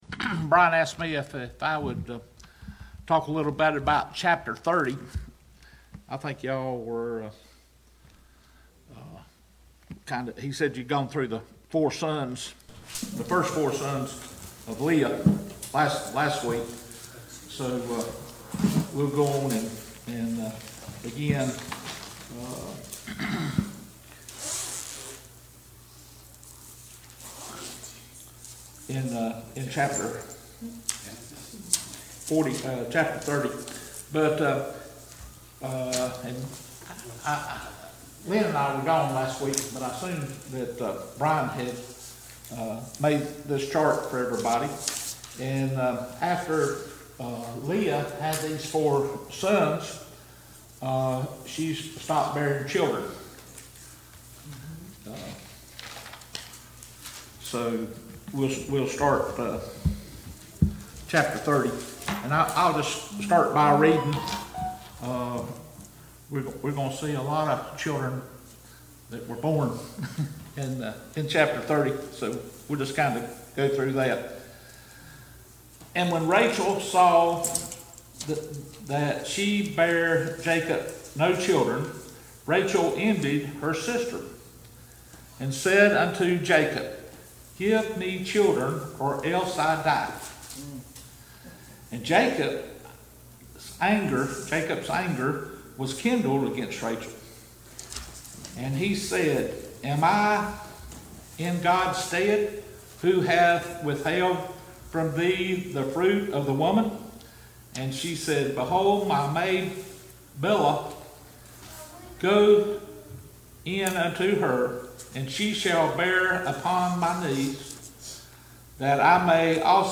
Study of Genesis Passage: Genesis 30 Service Type: Family Bible Hour « Be Humble Study of Paul’s Minor Epistles